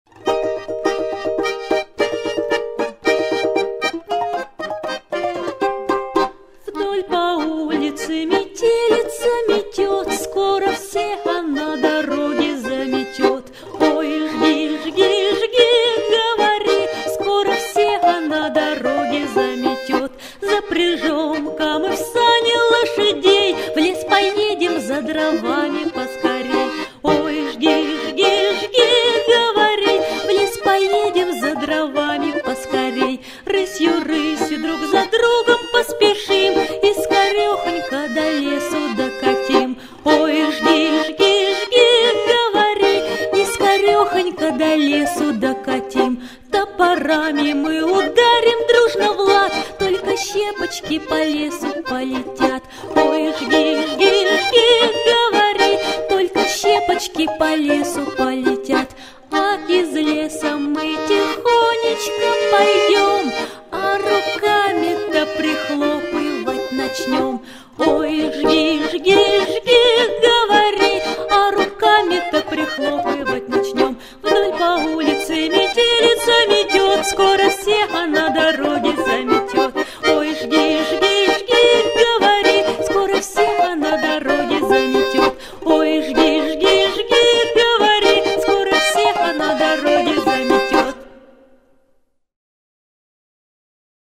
Метелица - русская народная песня - слушать онлайн